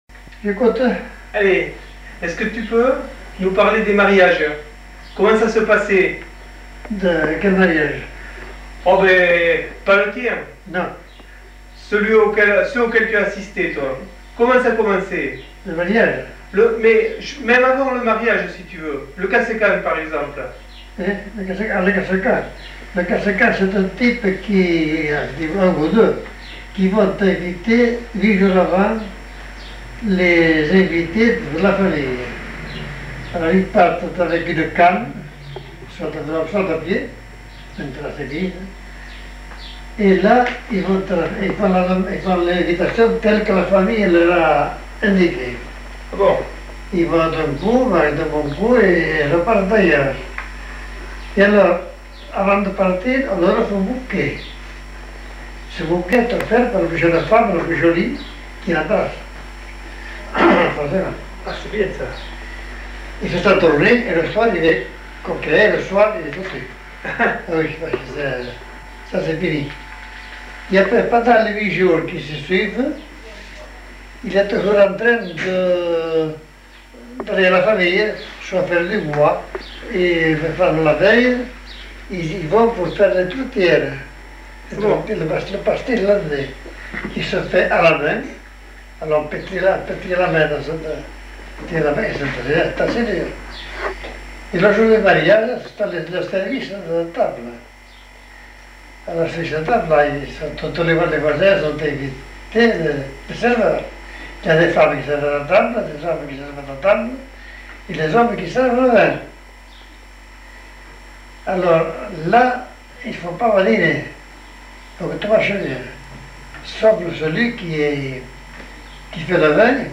Lieu : [sans lieu] ; Landes
Genre : témoignage thématique